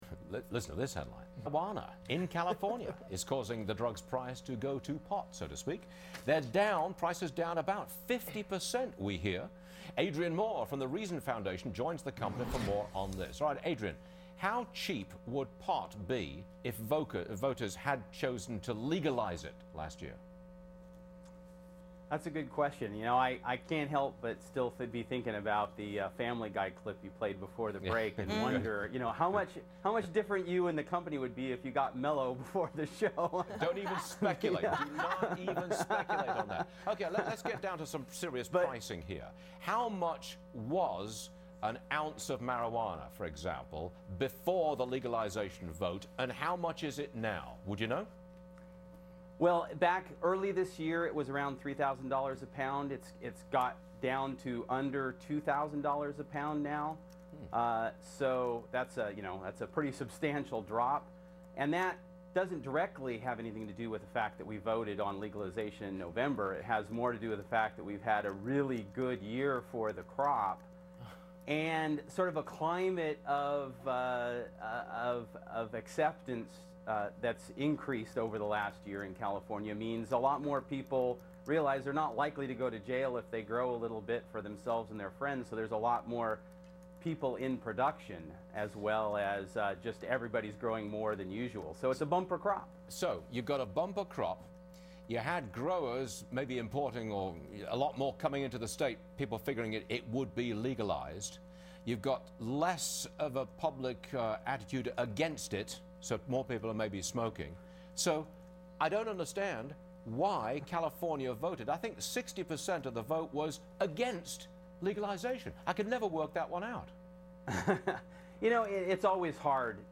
Air date: February 3, 2010 on Fox Business' Varney & Co.